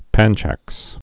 (pănchăks)